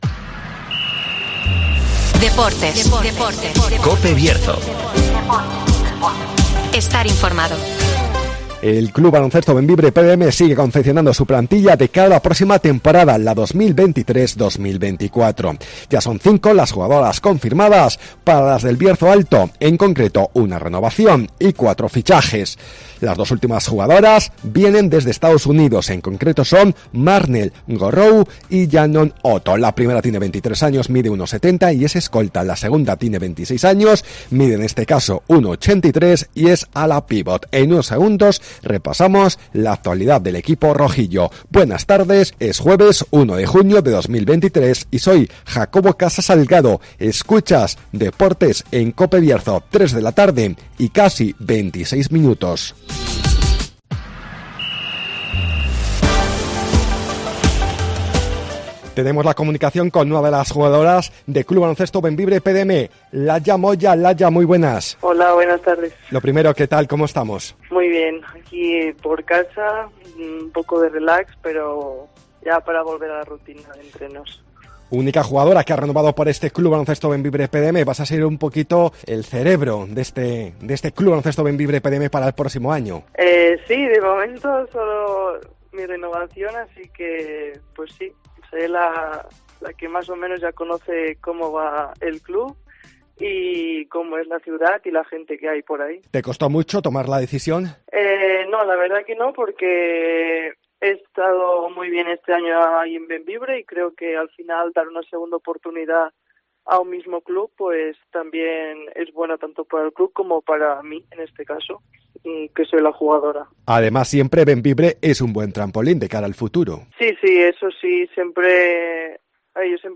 DEPORTES